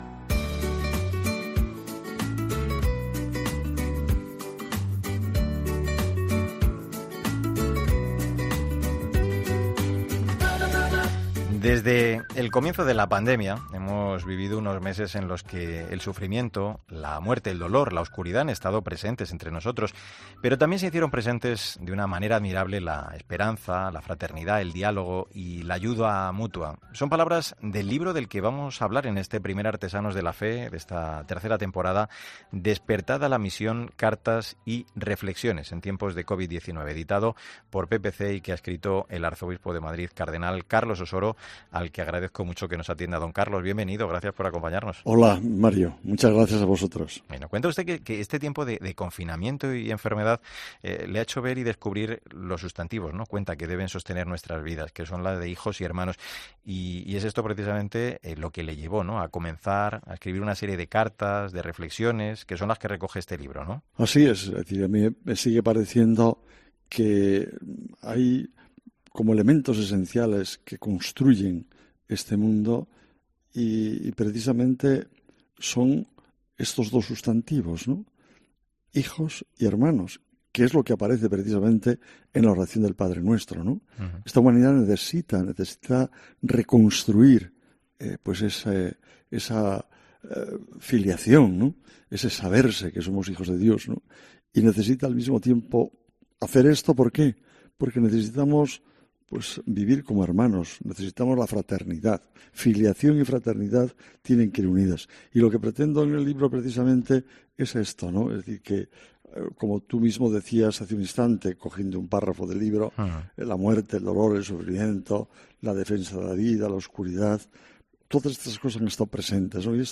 AUDIO: En este primer viernes de temporada, el tiempo de literatura nos ha acompañado el arzobispo de Madrid, cardenal Carlos Osoro, autor del...